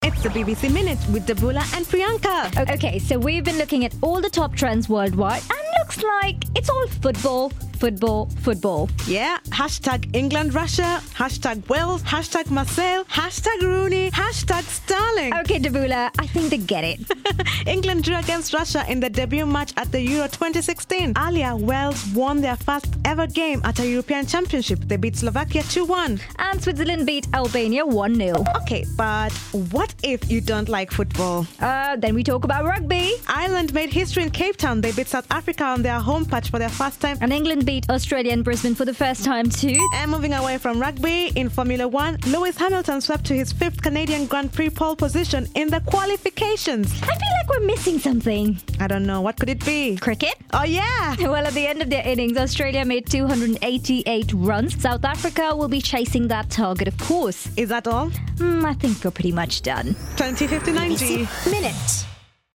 A minute of sport news.